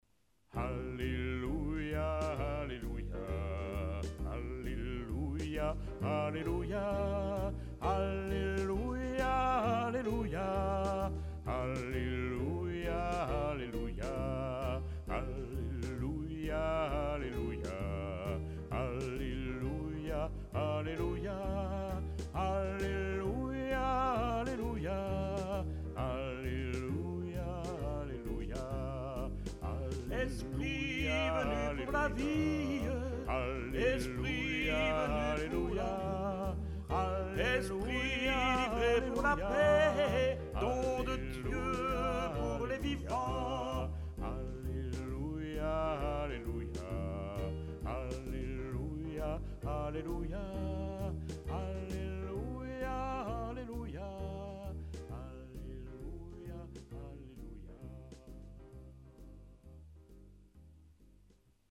les paroles (Paroles disponibles) , éventuellement les bandes son pupitres (en ligne au fur et à mesure)
la chorale chante toujours le refrain